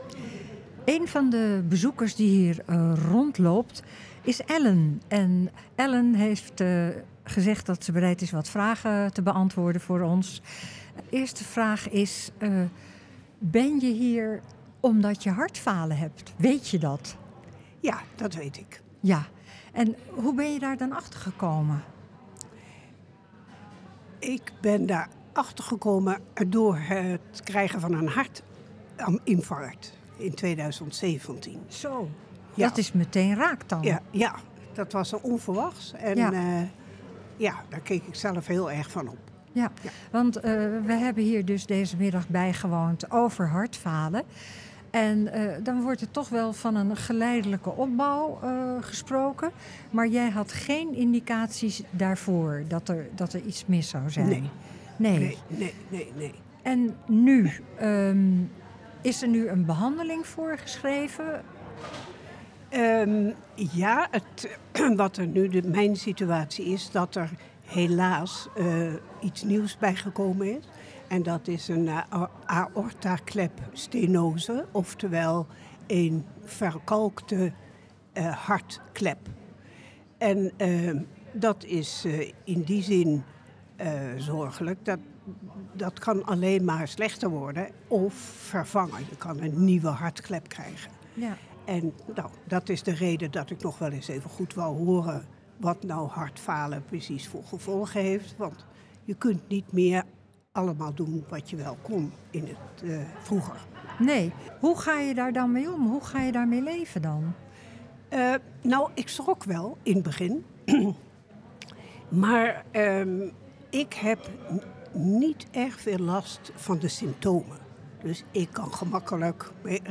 Tijdens de open dag over hartfalen in Tergooi MC waren veel bezoekers aanwezig.